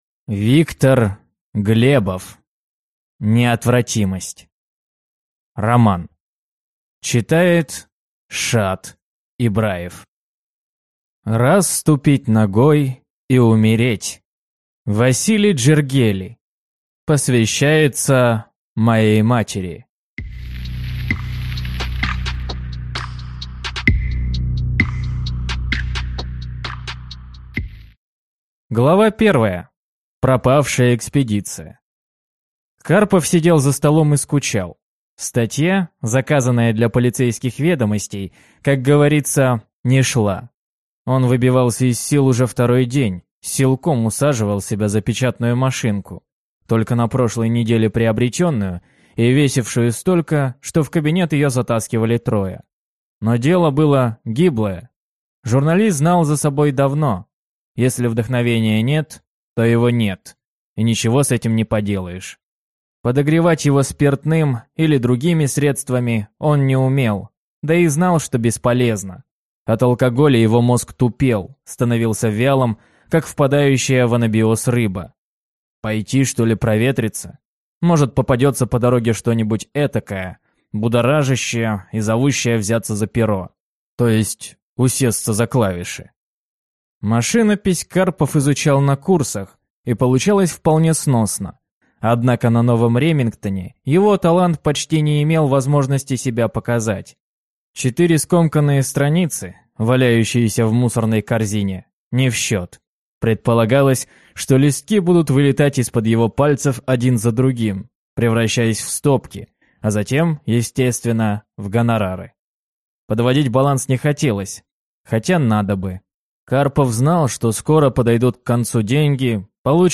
Аудиокнига Неотвратимость | Библиотека аудиокниг